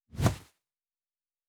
Wing Flap 4_8.wav